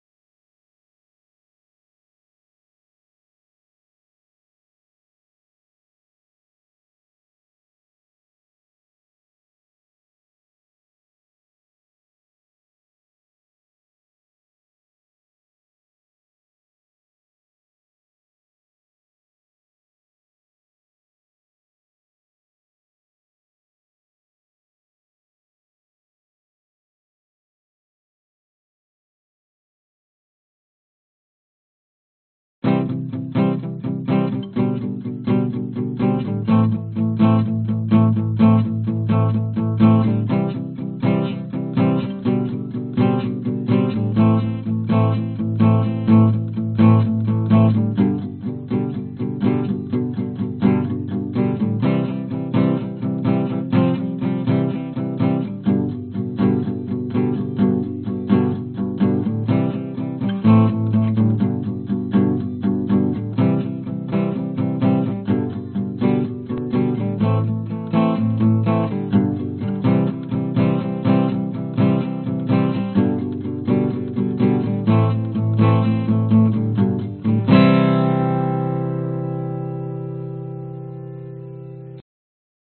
Tag: 吉他 节奏 原声 摇滚 日本 男性主唱